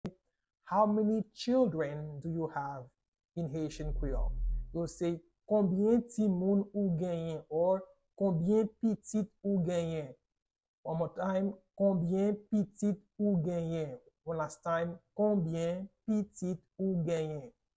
How to say “How many children do you have?” in Haitian Creole - “Konbyen pitit ou genyen?” pronunciation by a native Haitian Creole teacher
“Konbyen pitit ou genyen?” Pronunciation in Haitian Creole by a native Haitian can be heard in the audio here or in the video below: